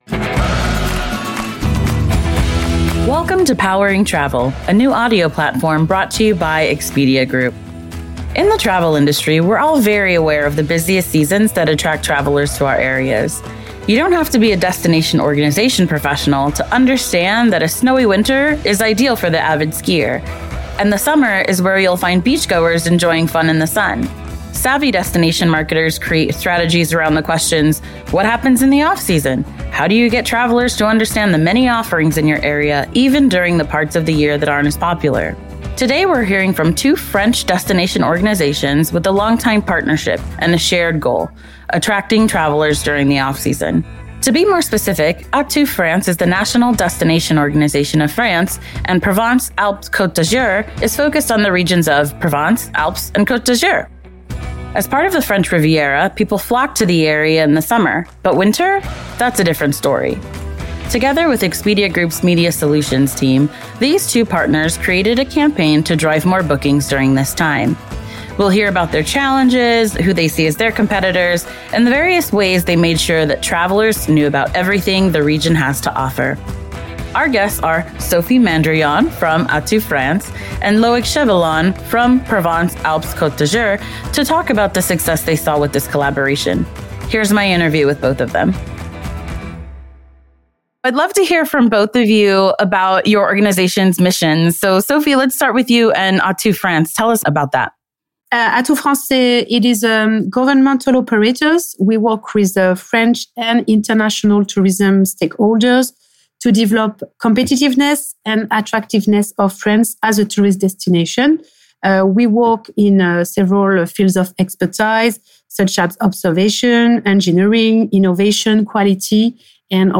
You can expect to hear travel industry experts speaking about topics ranging from travel trends and research data to tools and solutions that will change the way you approach your business. Each episode shares actionable tips to help industry leaders stay current and ultimately benefit the entire travel ecosystem—from CEOs to everyday travelers.